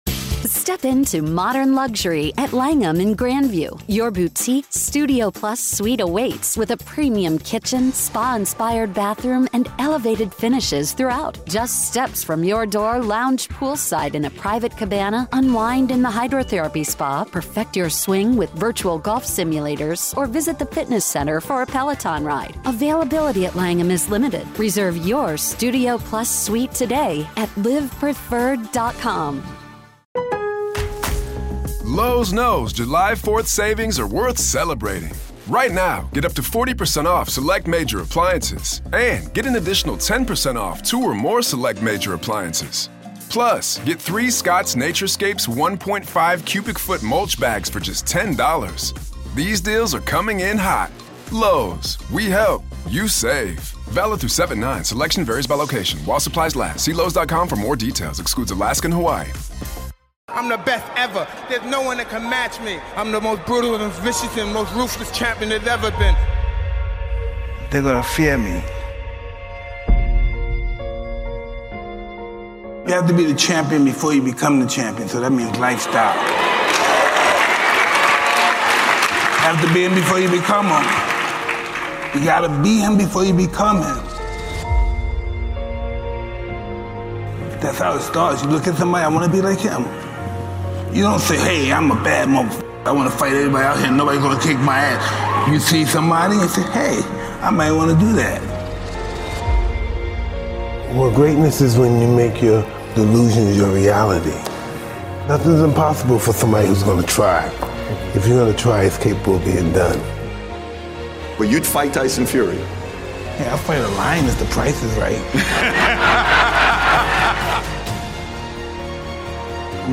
Refuse to lose and give it all you have, you will end up on top! This is a New Motivational Speech featuring the one and only, Mike Tyson.